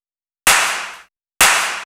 VTDS2 Song Kit 05 Male Sand Of Love Clap.wav